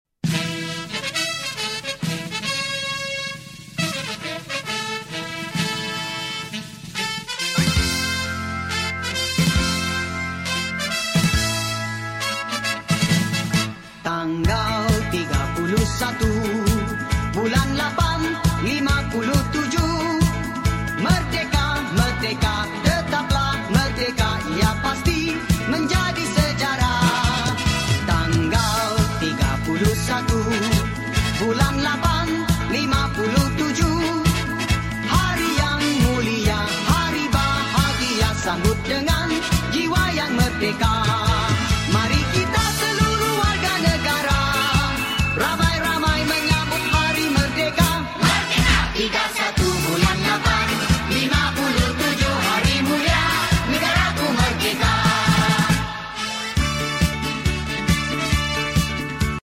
Yang di-Pertuan Agong Sultan Ibrahim menzahirkan ucapan Selamat Hari Kebangsaan Ke-68. Baginda bertitah kehidupan berbilang kaum yang harmoni menjadi tunjang perpaduan negara, selain mencerminkan keunikan Malaysia sebagai sebuah bangsa yang bersatu.